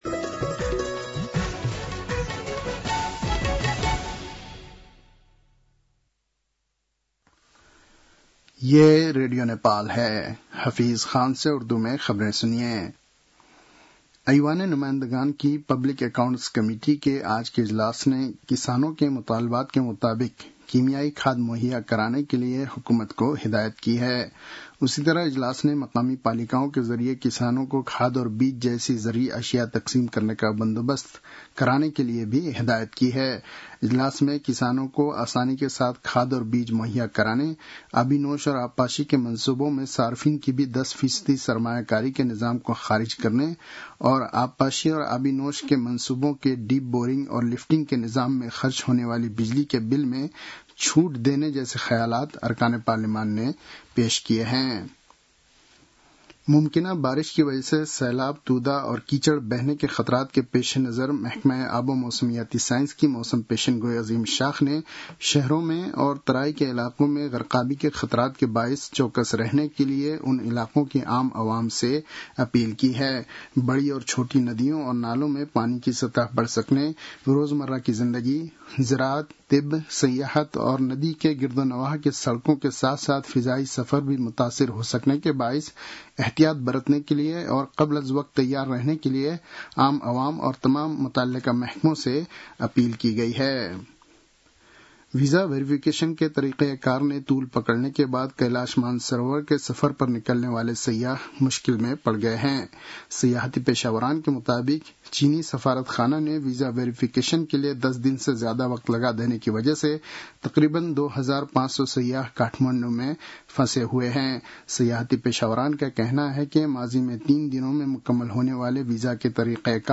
उर्दु भाषामा समाचार : २६ असार , २०८२
Urdu-news-3-26.mp3